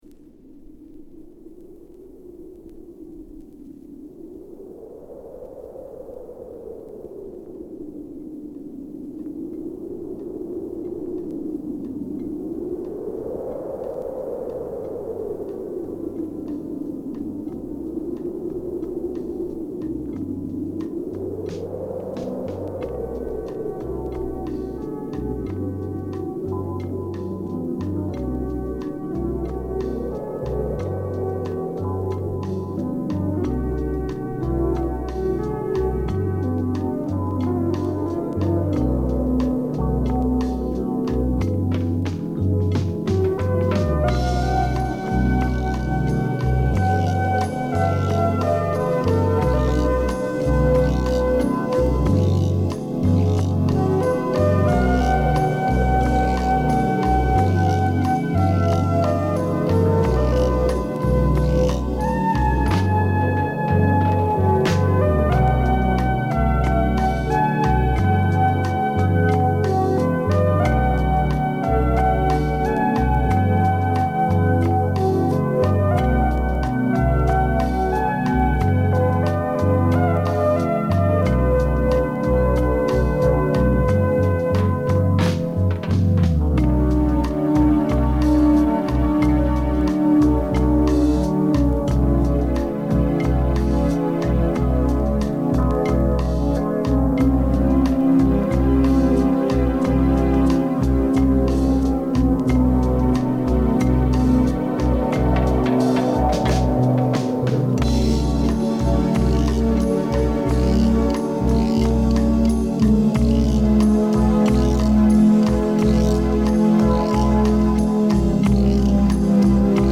Ambient , Cosmic , Down Tempo , Electronic
Mellow Groove